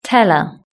Index of /platform/shared/pron-tool/british-english/sound/words